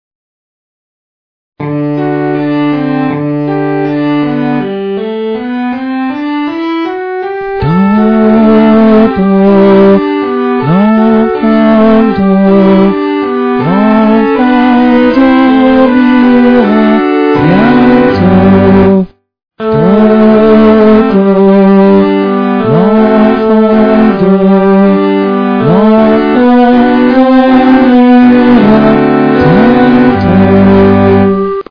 do_do_lenfant_song.mp3